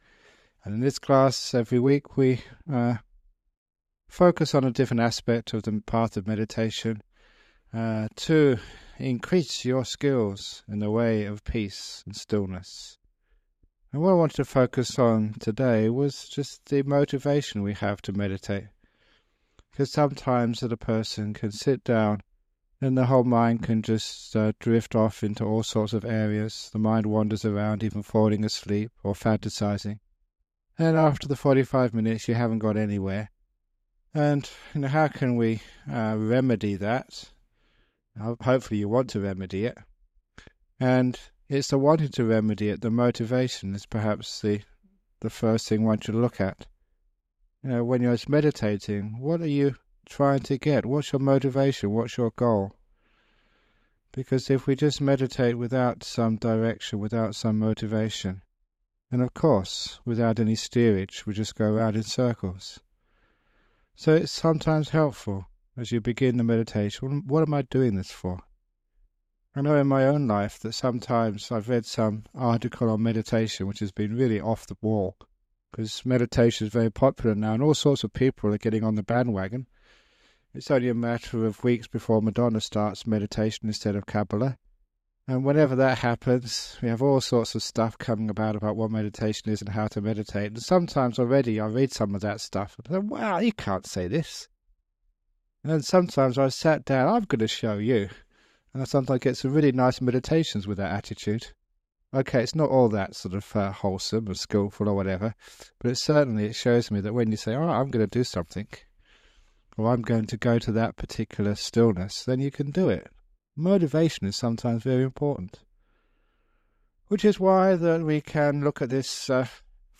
Guided Meditation
It includes a talk about some aspect of meditation followed by a 45 minute guided meditation. This guided meditation has been remastered and published by the Everyday Dhamma Network, and will be of interest to people who have started meditation but are seeking guidance to take it deeper.